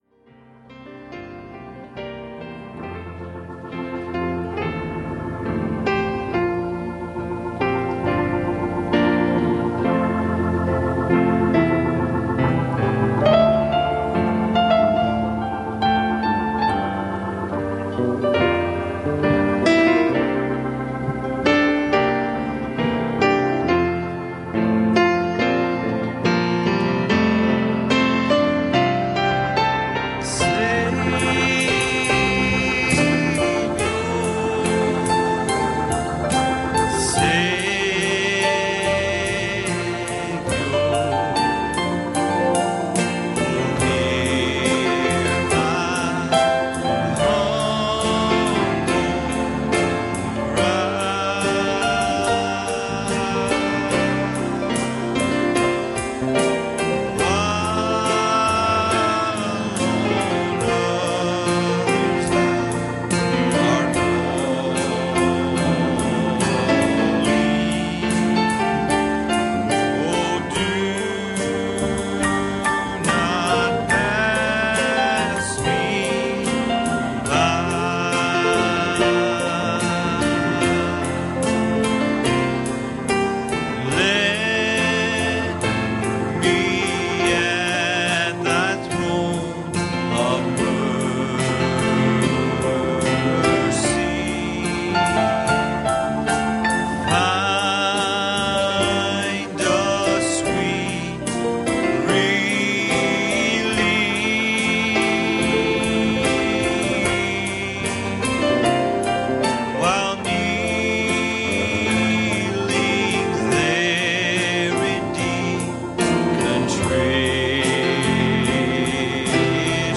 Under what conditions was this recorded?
Series: Wednesday Evening Services Service Type: Wednesday Evening